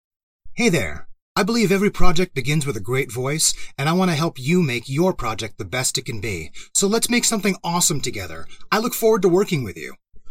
It is professional, articulate, trustworthy, assertive, warm, inviting and believable.
English (North American) Adult (30-50) | Older Sound (50+)
0825Conversational_Demo_Mastered_Final.mp3